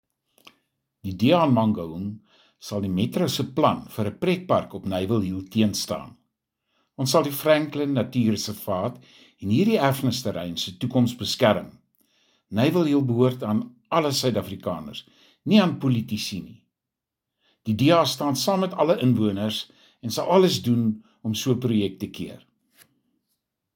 Afrikaans soundbites by Cllr Pieter Lotriet and Sesotho soundbite by Cllr Kabelo Moreeng.